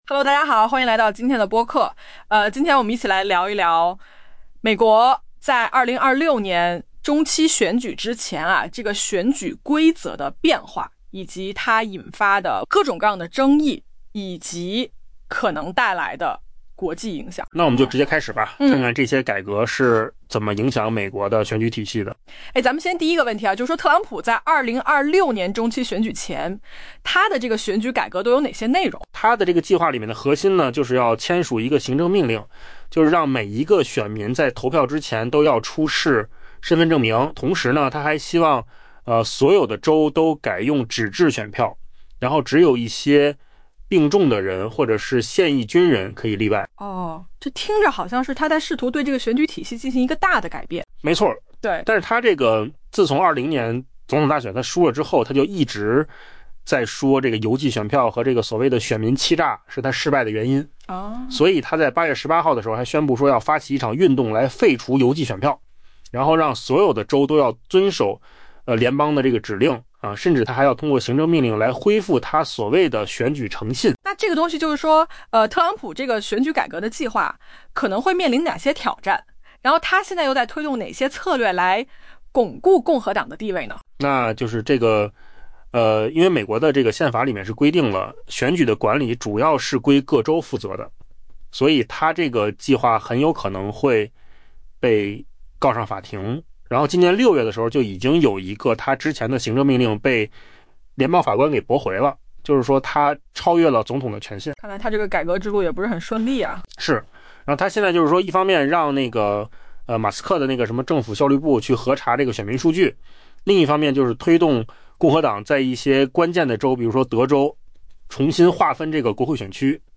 AI播客：换个方式听新闻 下载mp3
音频又扣子空间生成